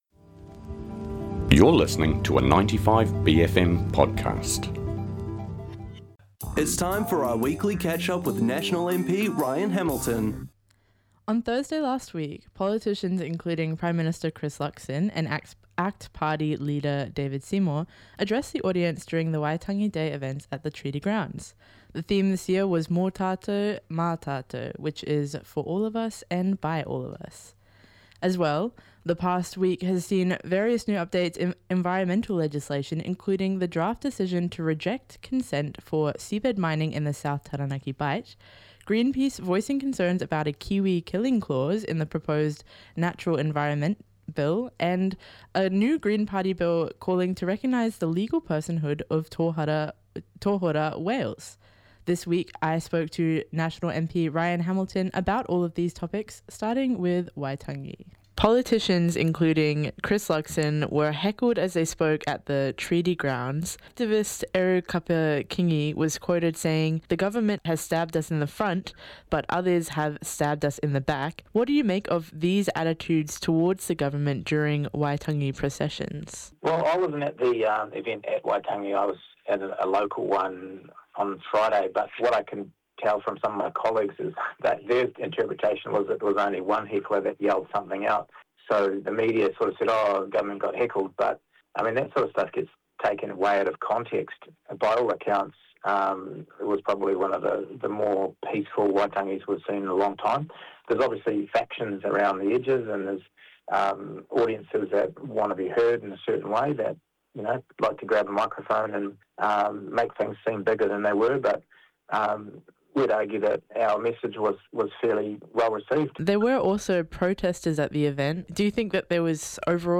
Waitangi Day & National Party views on recent environmental legislation w/ National MP Ryan Hamilton: 10 February 2026